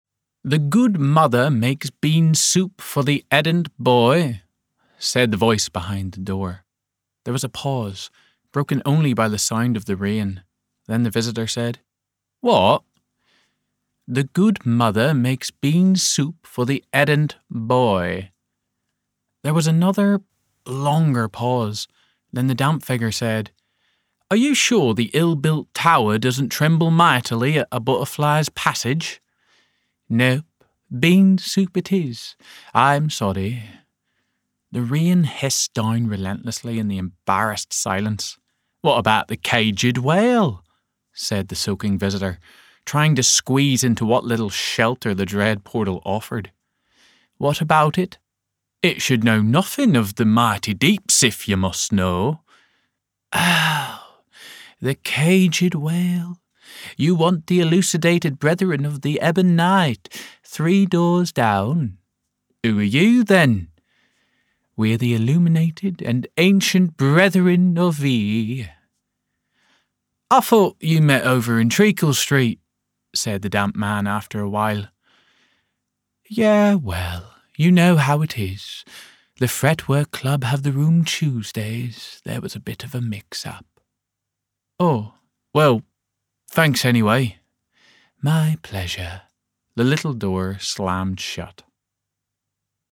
Audiobook Showreel
Male
Irish
Friendly